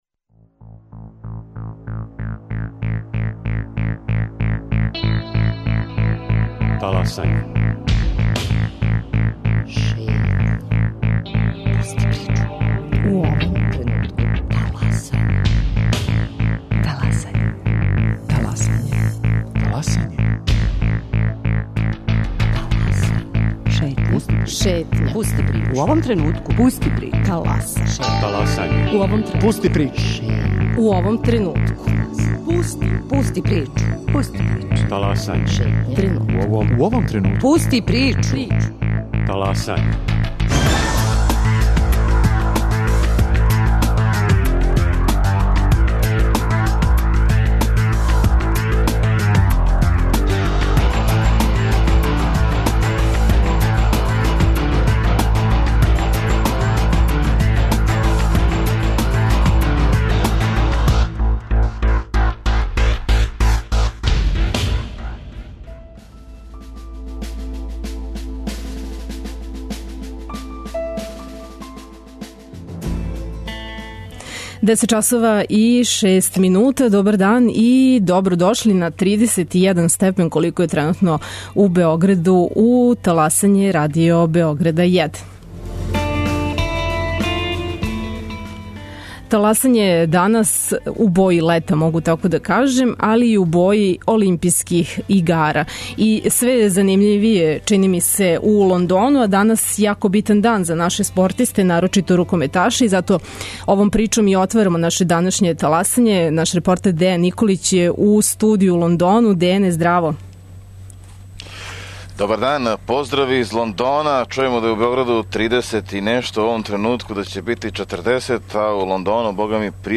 Прича број два долази с, по многима, наше најлепше планине, - Копаоника. На самом крају јула, на Копаонику су одржани Дани боровнице, трећи по реду.